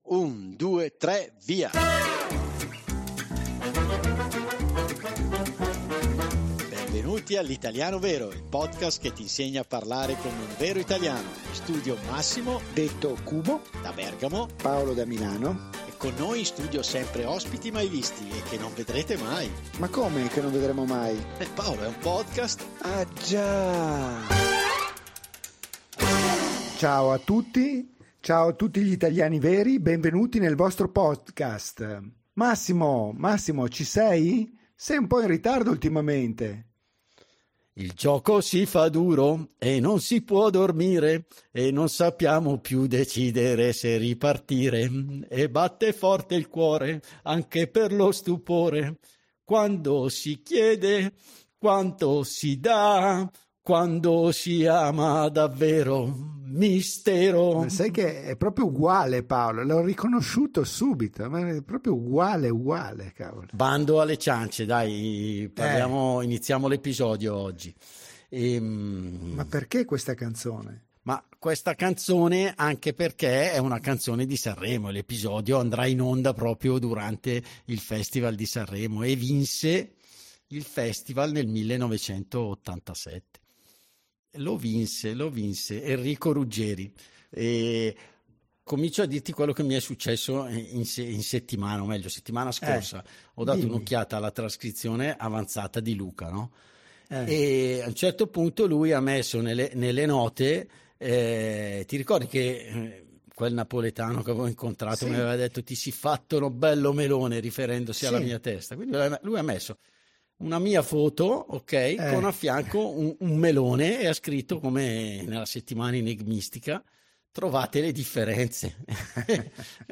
In questo episodio parliamo di nomi sovrabbondanti…ma tranquilli, allegria, canti e risate non lo sono mai 😉.